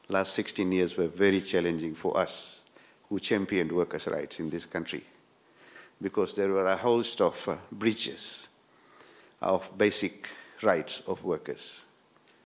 Speaking at the Evidence-Based Making for Decent Work ILO-Pacific Islands Regional Training underway in Nadi, Singh, who is also a trade unionist, says the last 16 years have been challenging.